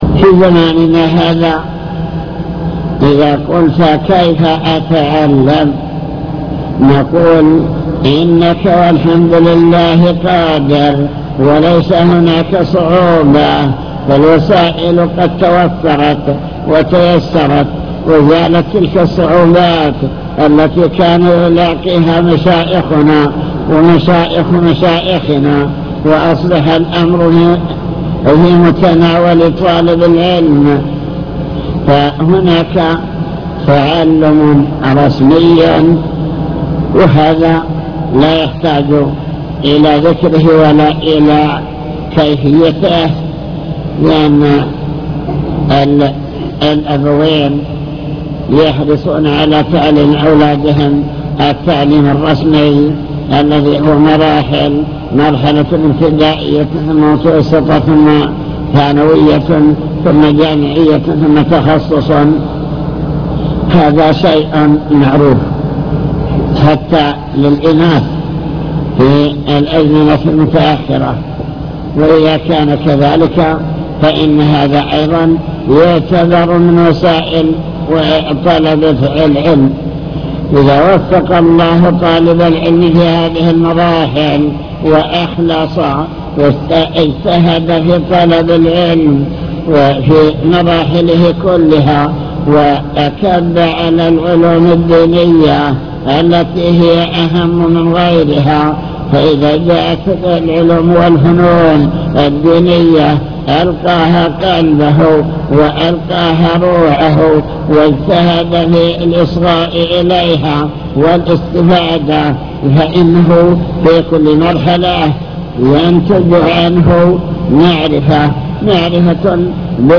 المكتبة الصوتية  تسجيلات - محاضرات ودروس  محاضرات عن طلب العلم وفضل العلماء بحث في: أن العمل والتطبيق هو ثمرة العلم